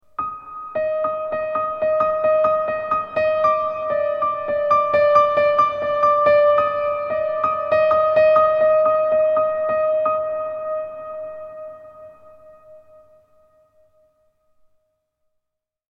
Tense Dramatic Piano Musical Phrase
Description: Tense dramatic piano musical phrase. Mysterious horror melody on high piano notes creates a chilling, tense, and suspenseful atmosphere.
Genres: Sound Effects
Tense-dramatic-piano-musical-phrase.mp3